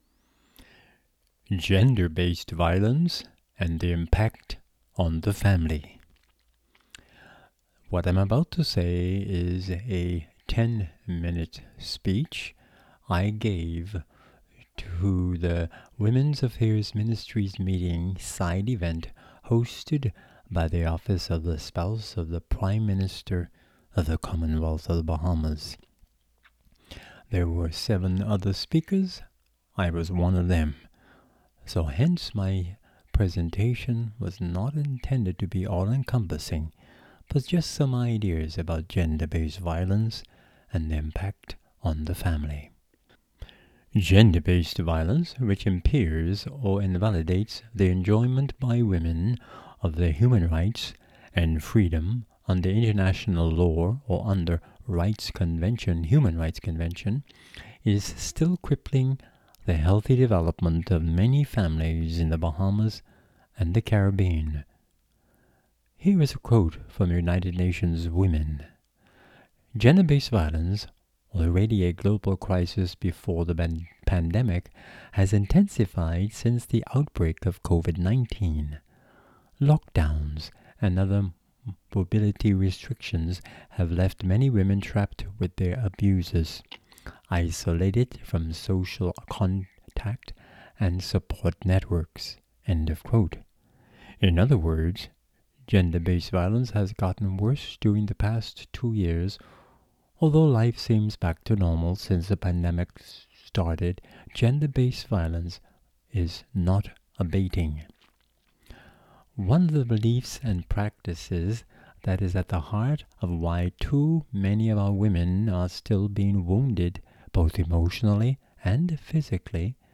A speech given at the 13th Commonwelath Women's Affiairs Ministerial Meeting high level side even, Nassau, The Bahamas